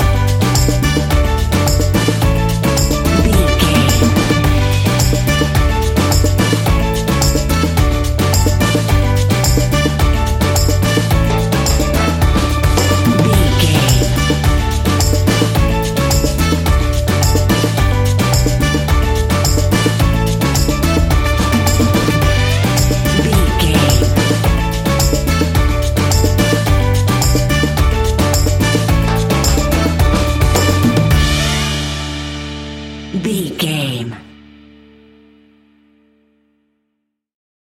Ionian/Major
steelpan
drums
percussion
bass
brass
guitar